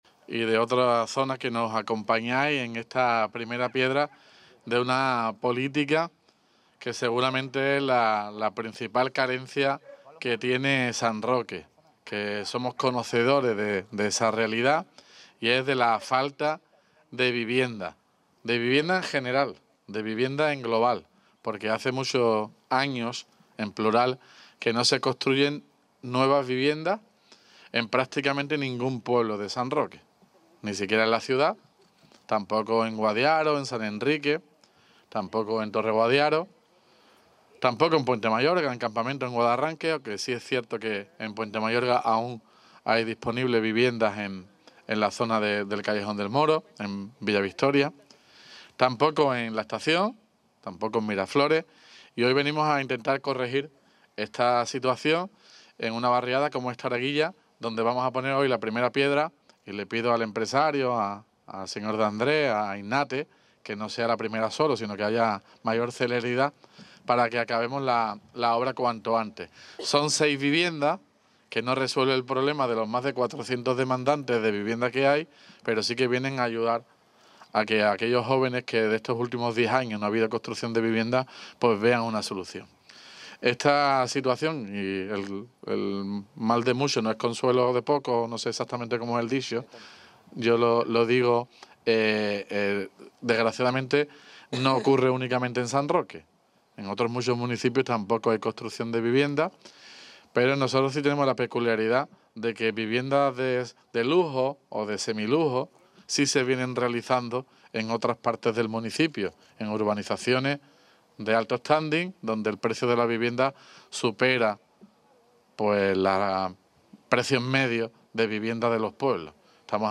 PRIMERA_PIEDRA_TARAGUILLA_TOTAL_ALCALDE.mp3